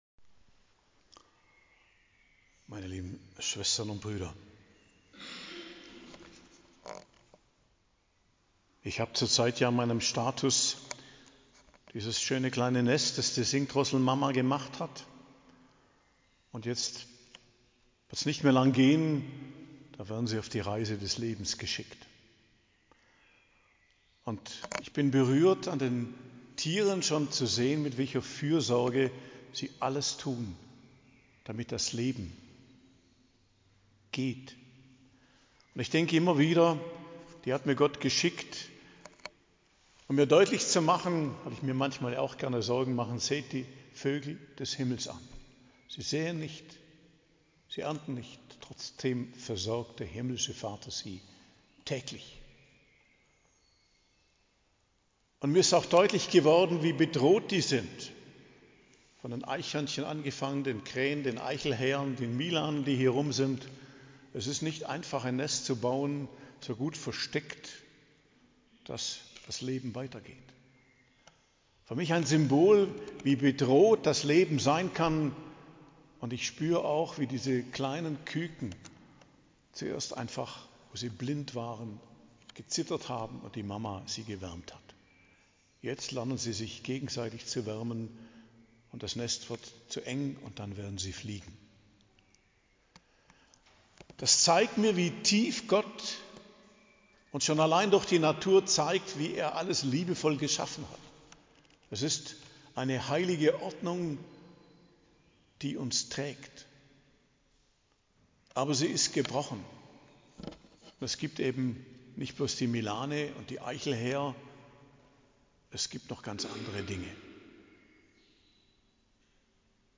Predigt am Hochfest Heiligstes Herz Jesu, 27.06.2025